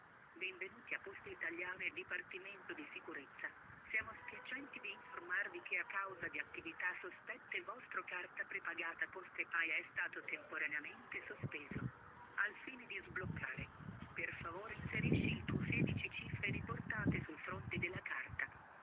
Telefonando al numero indicato nel messaggio di posta elettronica fraudolento si ascolta il seguente messaggio registrato:
La voce femminile che recita il messaggio presenta un inequivocabile accento straniero, presumibilmente dell’Europa dell’Est,  particolare che potrebbe anche non portare ad essere sospettosi dal momento che molte società hanno affidato servizi di call center a società operanti all’estero o facenti uso di operatori stranieri.
vishing_poste.mp3